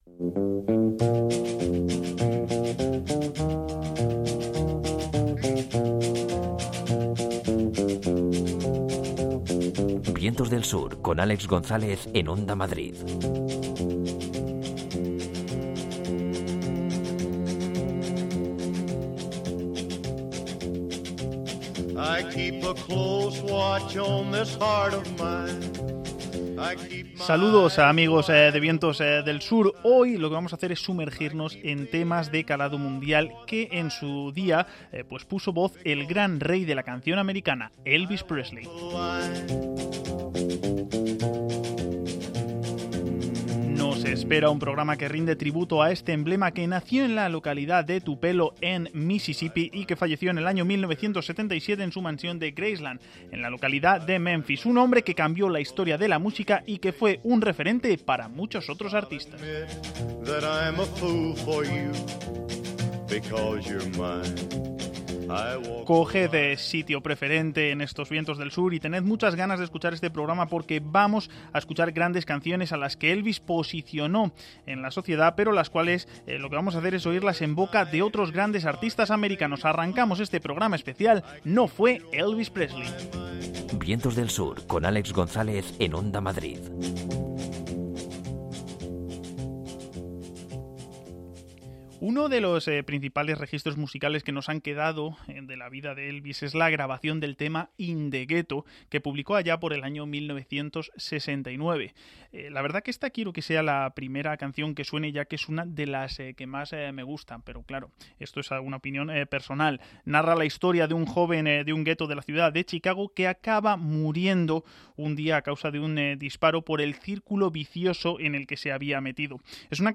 rock and roll
Góspel